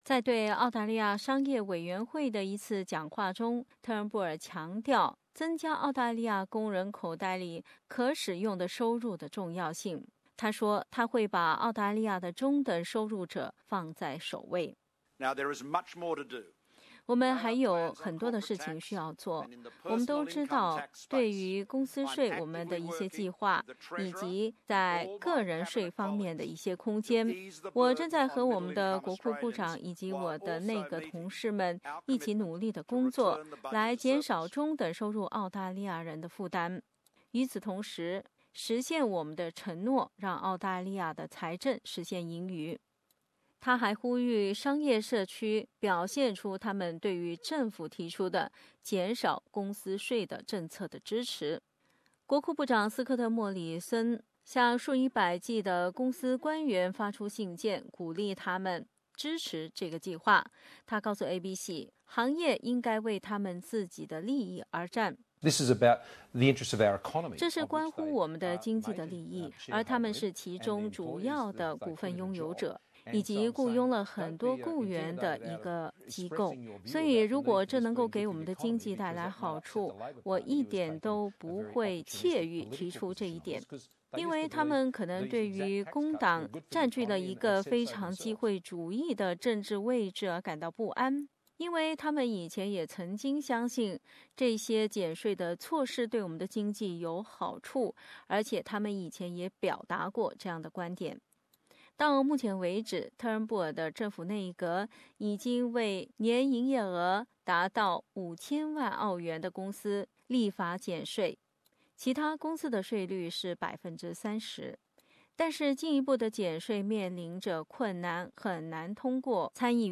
的报道